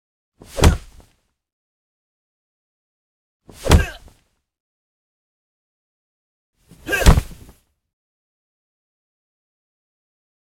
挨打击打声音音效免费下载
SFX音效